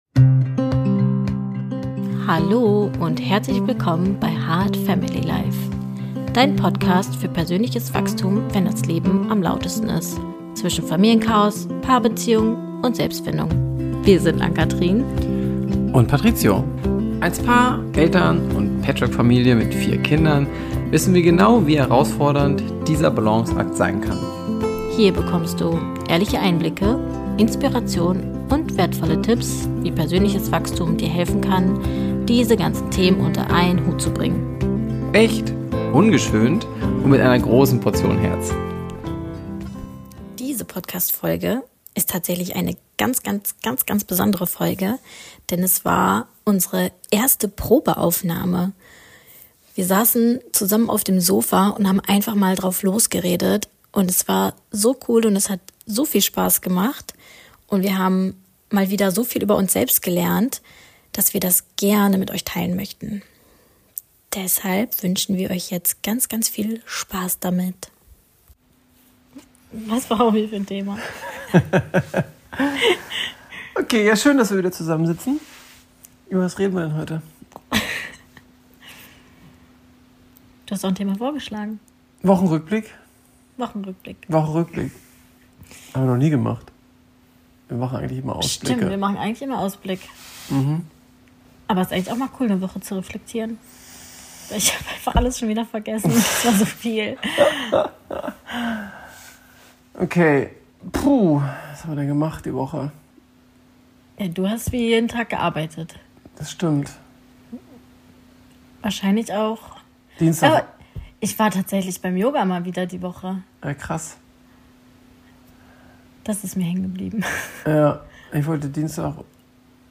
Es war unsere erste Podcast Testaufnahme – am Ende ist ein ehrlicher, witziger und tiefgehender Wochenrückblick daraus entstanden.